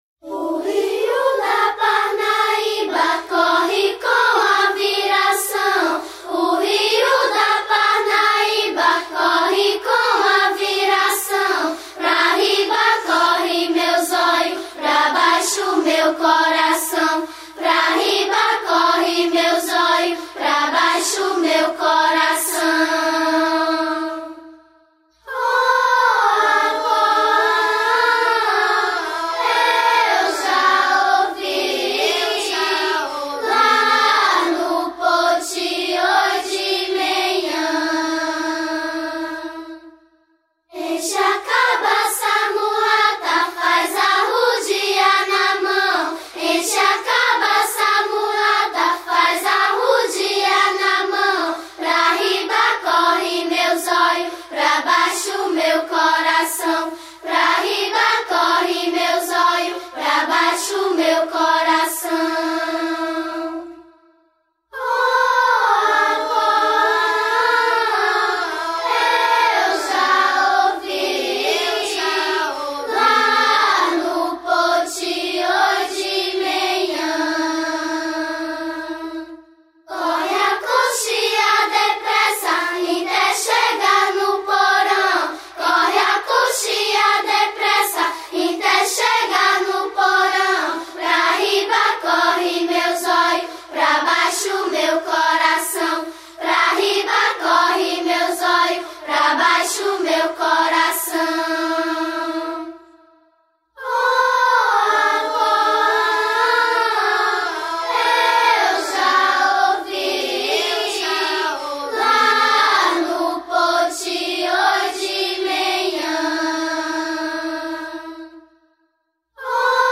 713   02:14:00   Faixa:     Folclore Piauiense